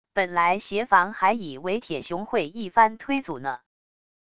本来叶凡还以为铁熊会一翻推阻呢wav音频生成系统WAV Audio Player